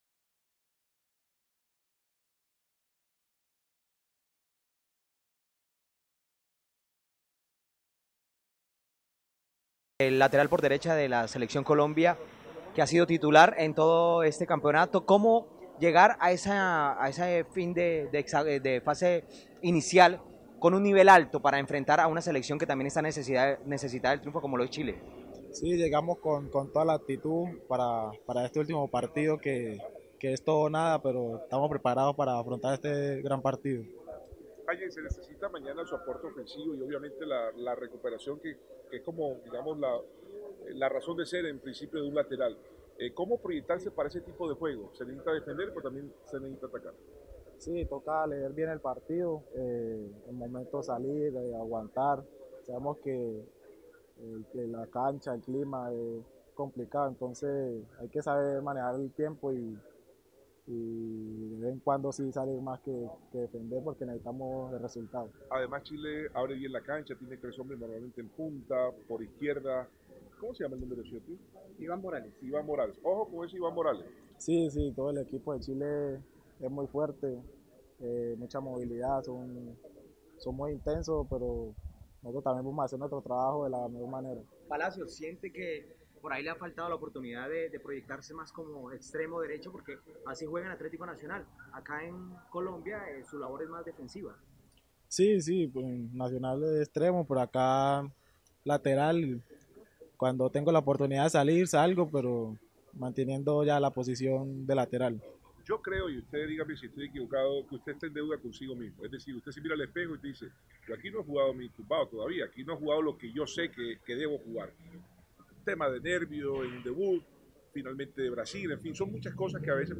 Luego del entrenamiento, cuatro jugadores atendieron a la prensa en su concentración: